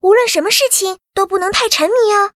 文件 文件历史 文件用途 全域文件用途 Fifi_fw_03.ogg （Ogg Vorbis声音文件，长度2.3秒，104 kbps，文件大小：30 KB） 源地址:游戏语音 文件历史 点击某个日期/时间查看对应时刻的文件。